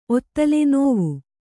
♪ ottale nōvu